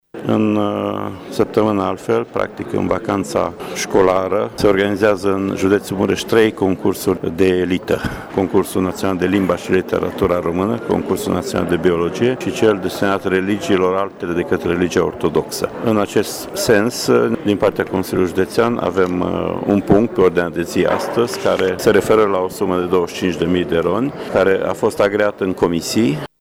Inspectorul general școlar Ștefan Someșan a precizat că la olimpiadă vor participa 220 de elevi şi 83 de profesori din toate judeţele.